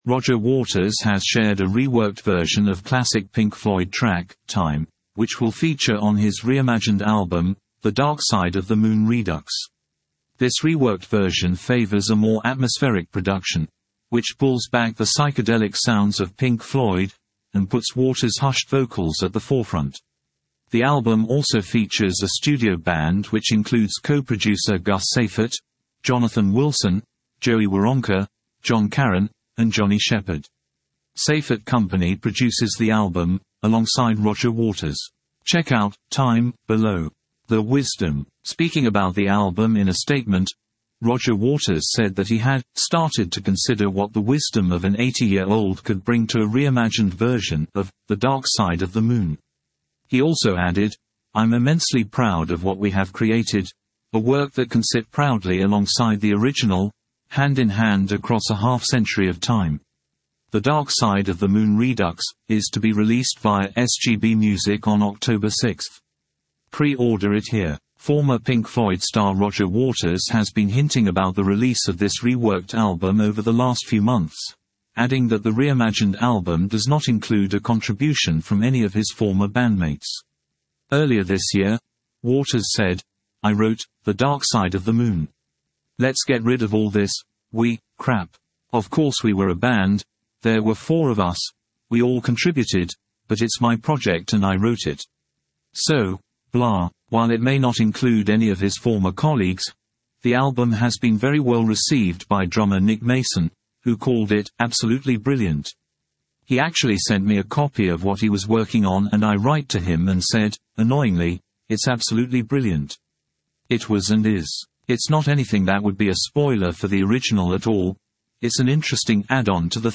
This reworked version favours a more atmospheric production
hushed vocals at the forefront
The album also features a studio band